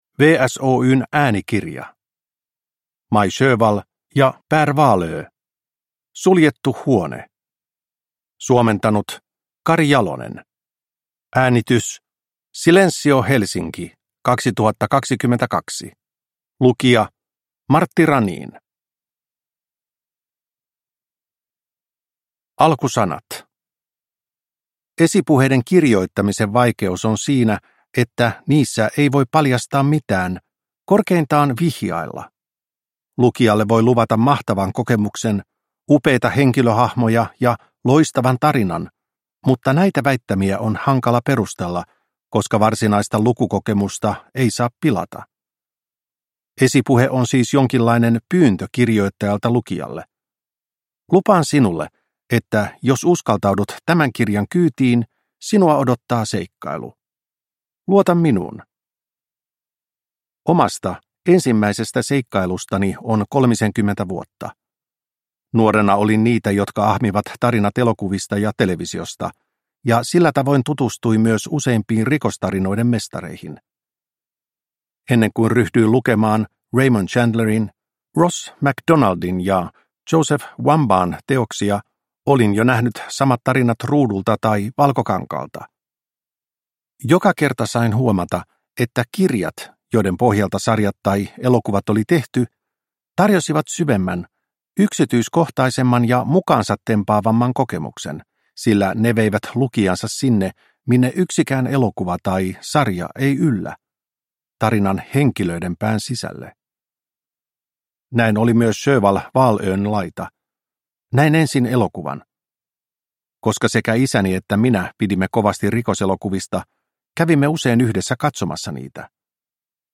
Suljettu huone – Ljudbok – Laddas ner